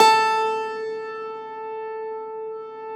53e-pno13-A2.wav